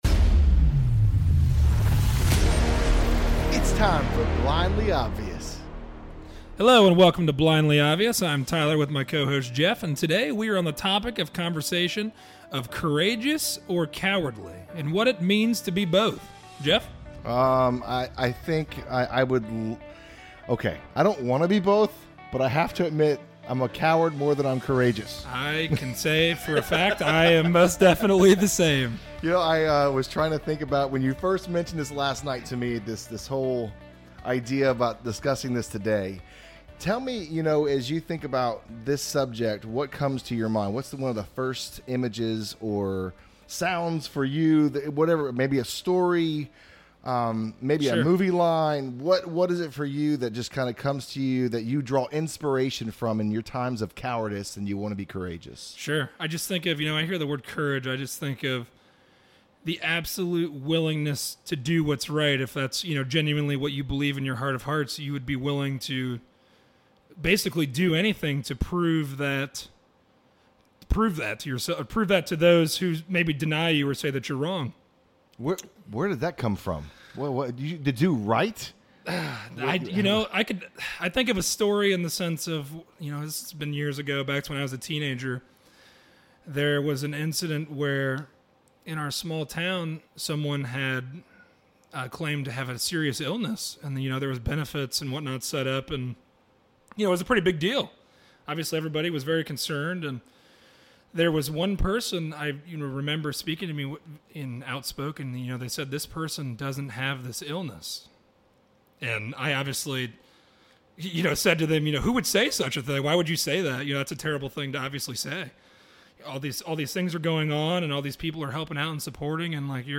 A conversation on being Courageous or Cowardly. Are we going to do what is actually right, or right for us?